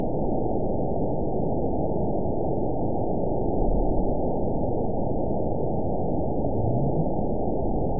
event 920445 date 03/25/24 time 19:56:58 GMT (1 year, 1 month ago) score 9.40 location TSS-AB01 detected by nrw target species NRW annotations +NRW Spectrogram: Frequency (kHz) vs. Time (s) audio not available .wav